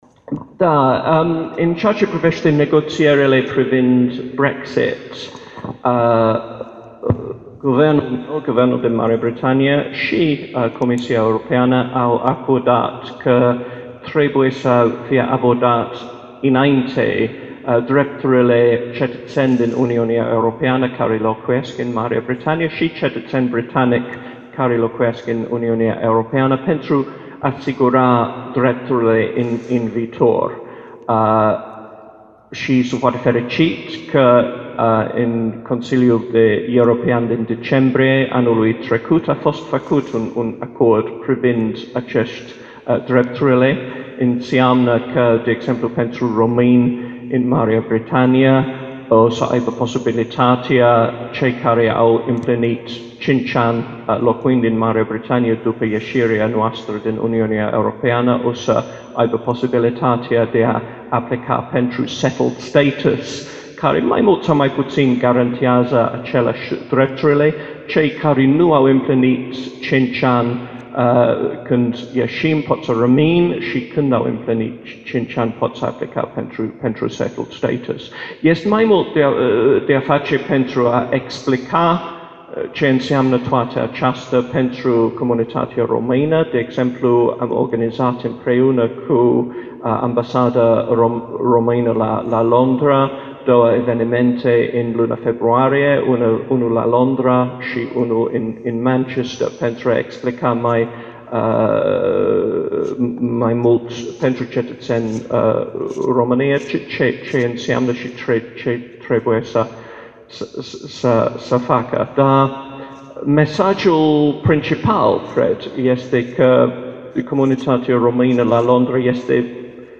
Conferința ambasadorului Marii Britanii, Paul Brummell( foto) pe teme de istorie, diplomație, dar și sociale, s-a desfășurat aștăzi în Sala a Voievozilor a Palatului Culturii.